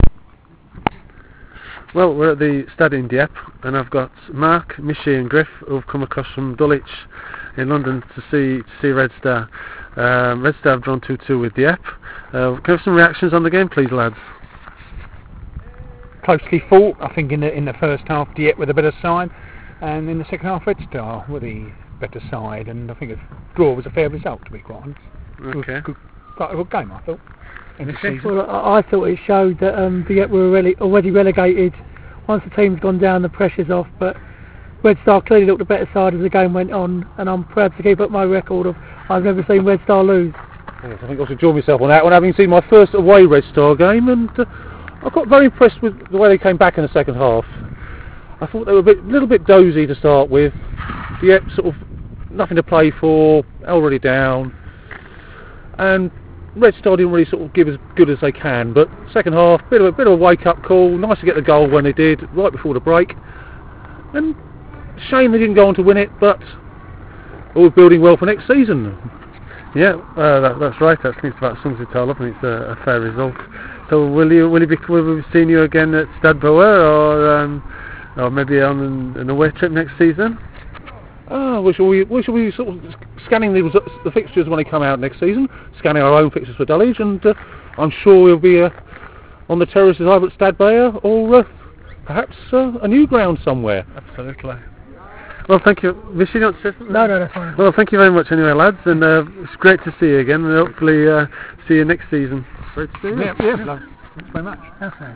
A short interview with our intrepid voyagers can be found
dulwichindieppe.wav